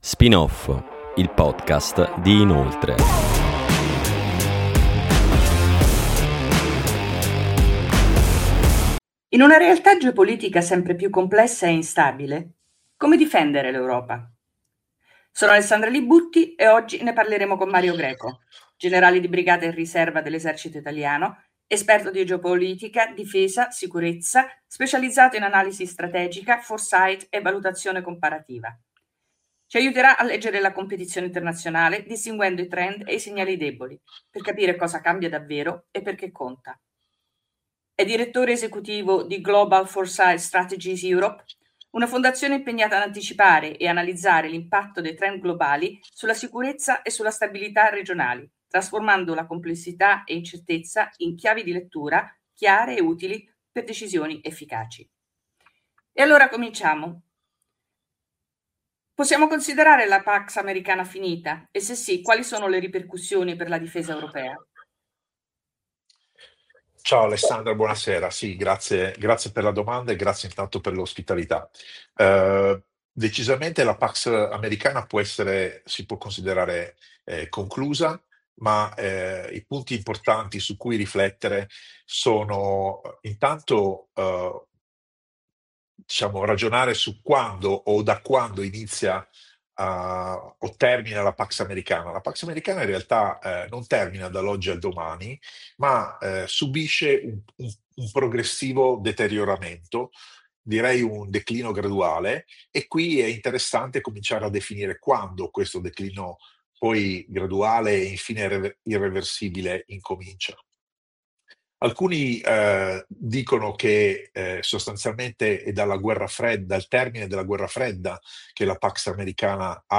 [00:00:00] Speaker A: Spin Off, il podcast di Inoltre.